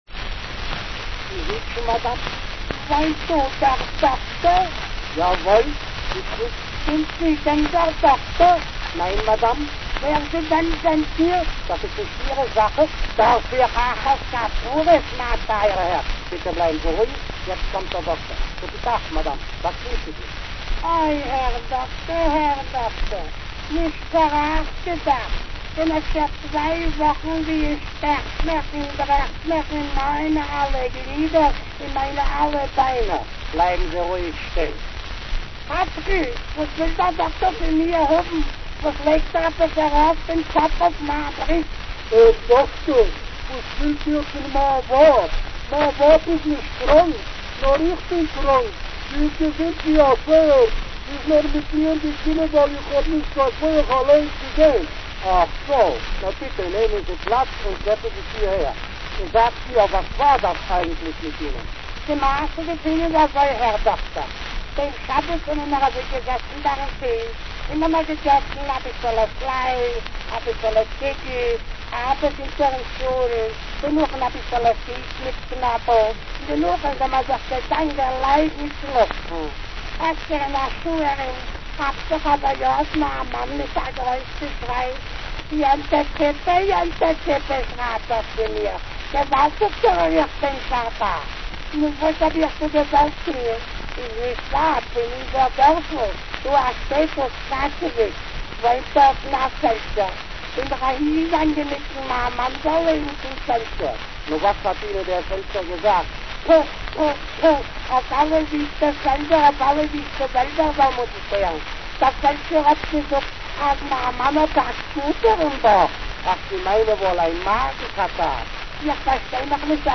Kleinkunst und Komik:
Eine russische Dacapo - der humoristische Vortrag in jiddisch - eine jetzt wohl fast ausgestorbene Sprache